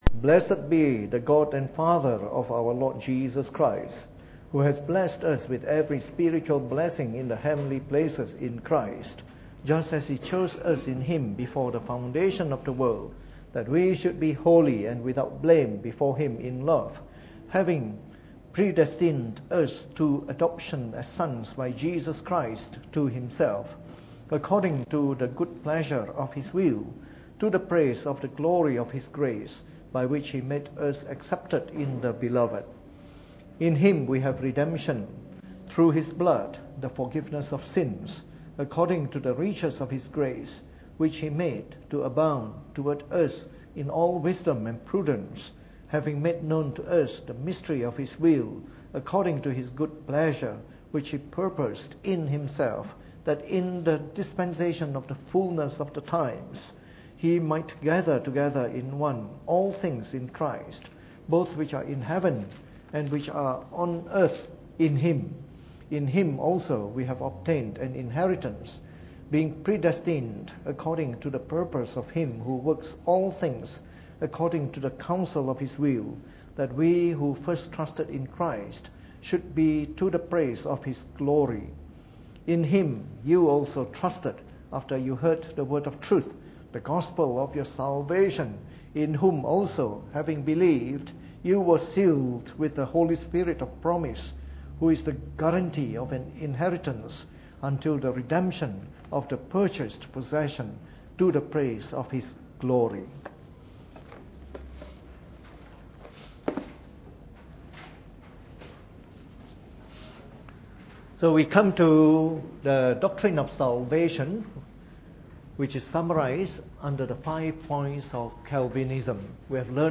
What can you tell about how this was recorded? Preached on the 24th of August 2016 during the Bible Study, from our series on the Five Points of Calvinism.